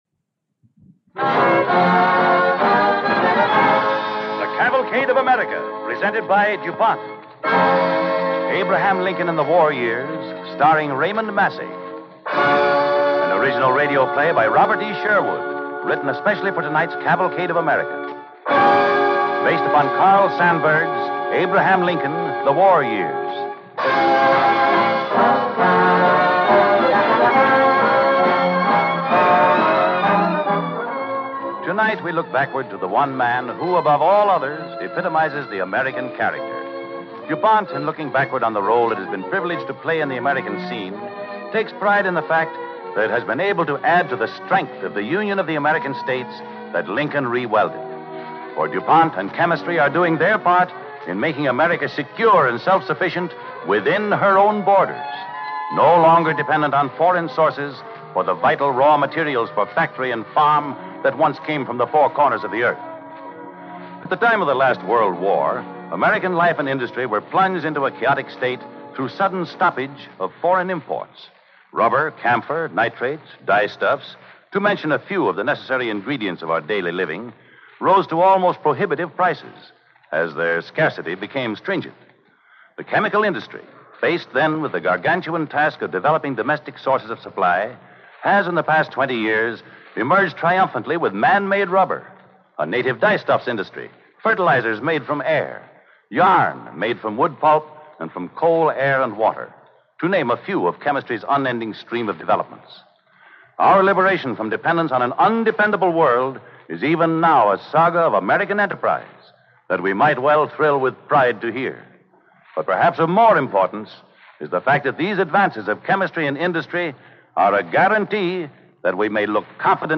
Abraham Lincoln, The War Years, starring Raymond Massey